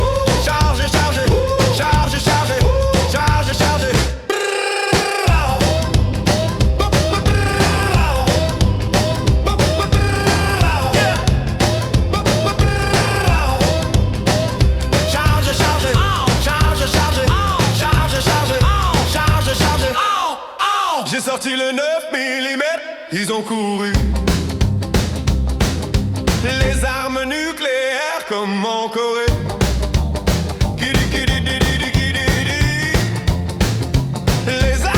Rock Country
Жанр: Рок / Кантри